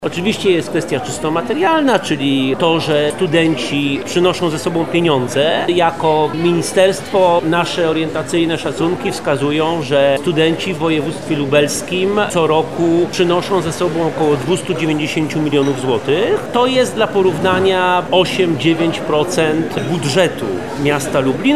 Konferencja pod tytułem „Studenci zagraniczni w Lublinie – potencjał doświadczeń i nowe perspektywy”, która odbyła się 5 grudnia na Wydziale Politologii i Dziennikarstwa UMCS była polem do dyskusji na temat wad i zalet przyjmowania obcokrajowców przez polskie uczelnie.
– mówi Andrzej Szeptycki, Podsekretarz Stanu w Ministerstwie Nauki i Szkolnictwa Wyższego.